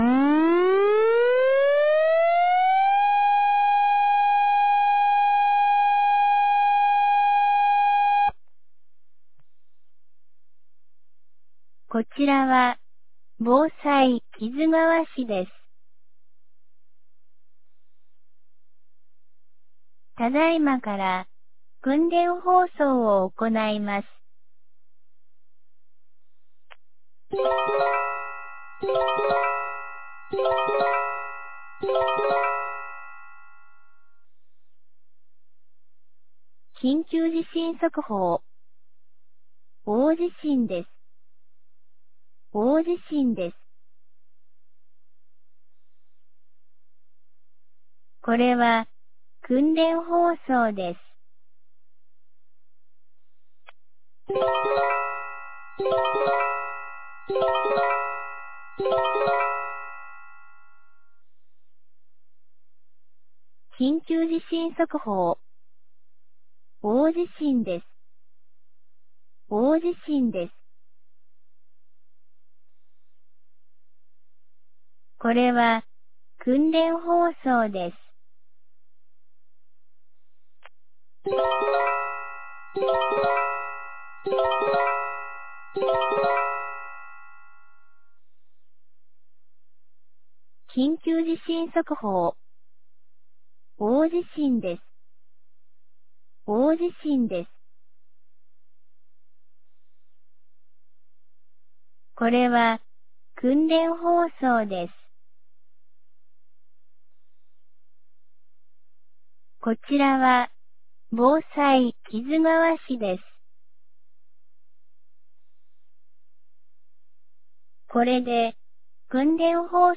2025年11月05日 10時02分に、木津川市より市全域へ放送がありました。
放送音声